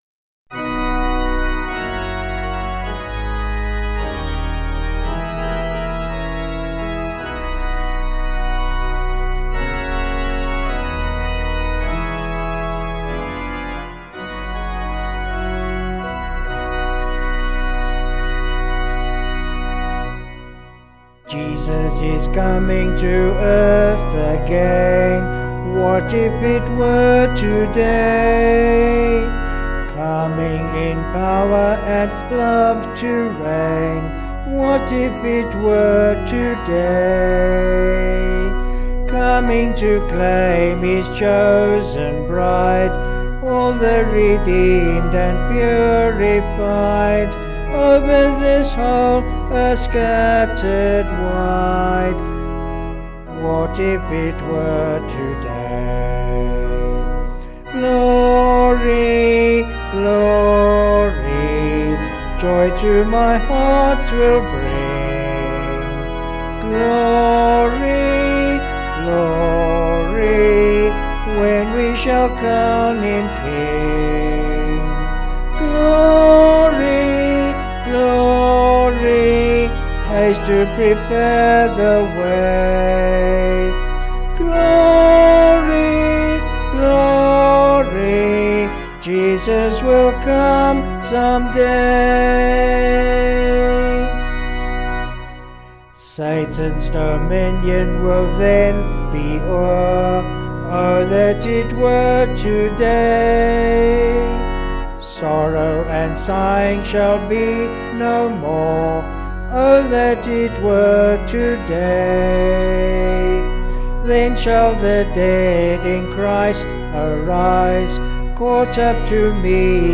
Vocals and Organ   265kb Sung Lyrics